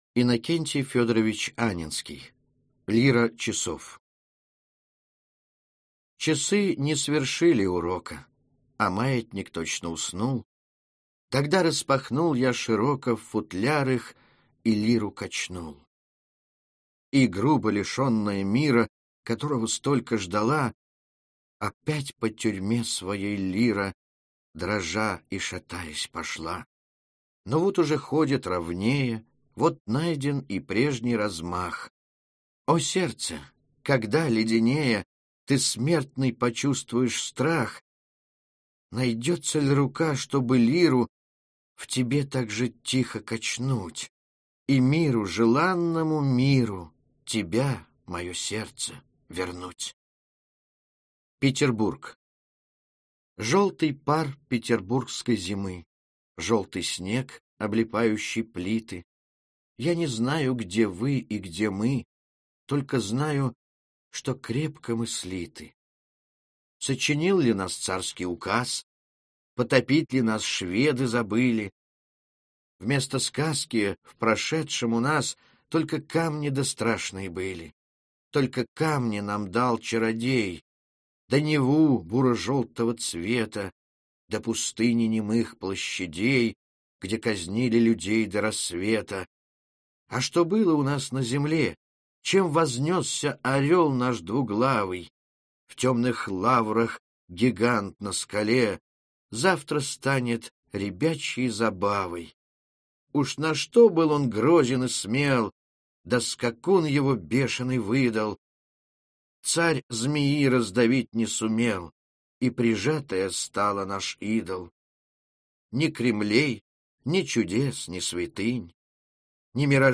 Аудиокнига Хрестоматия по Русской литературе 11-й класс | Библиотека аудиокниг